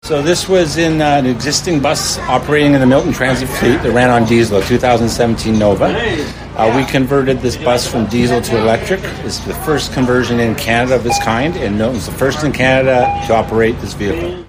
During the Downtown Milton SummerFest in June, FM 101 Milton had the chance to step inside the bus and look around.